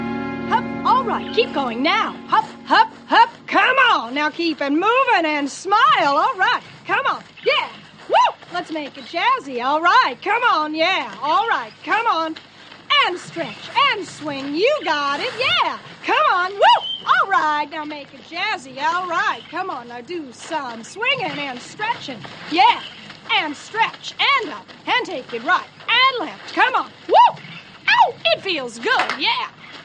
[There’s a women’s exercise class going on.]
This instructor is way too enthusiastic. She literally does not stop talking for the entire four minute scene. I set up Soundflower just so I could record this.
4x15-jazzy.mp3